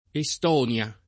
Estonia [ e S t 0 n L a ] top. f.